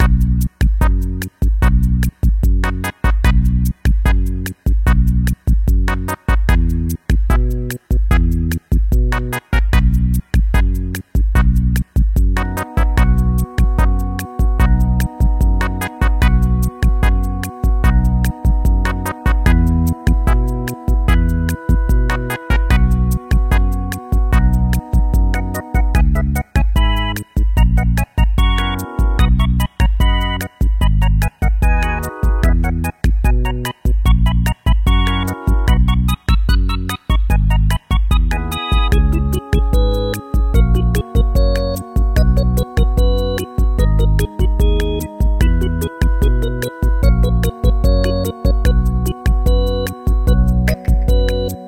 Video Game Music